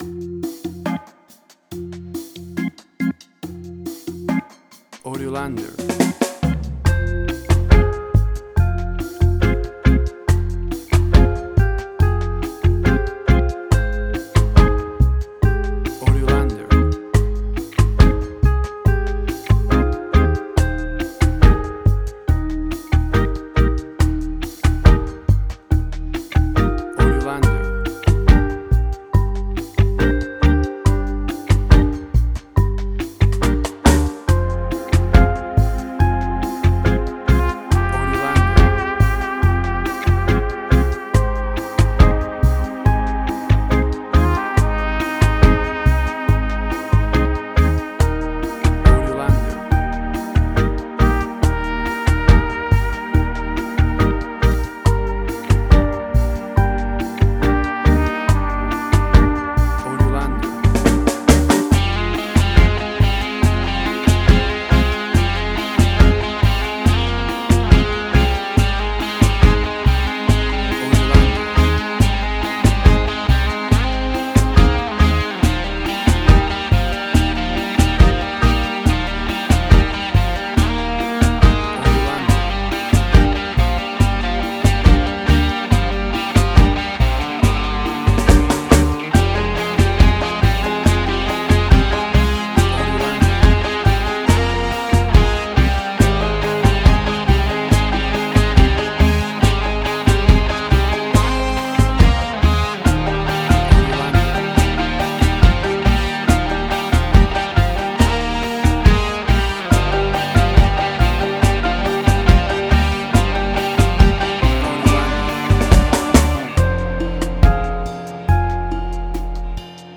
Reggae caribbean Dub Roots
Tempo (BPM): 70